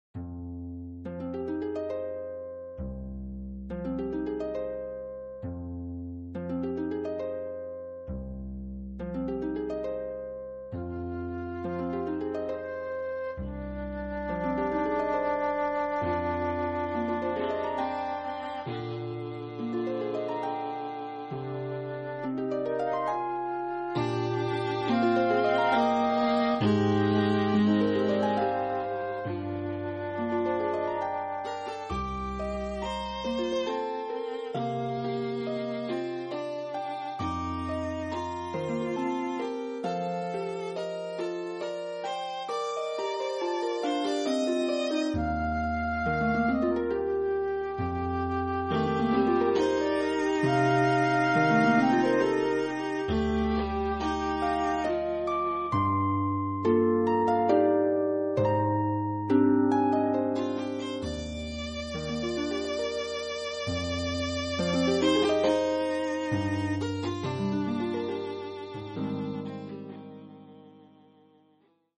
Andante, Scherzoso, Andante